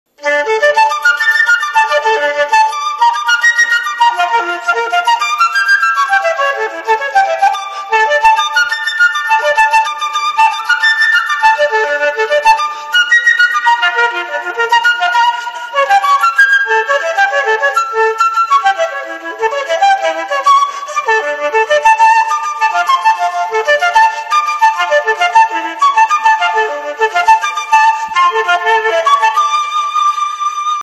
• Качество: 192, Stereo
громкие
без слов
скрипка
инструментальные
звонкие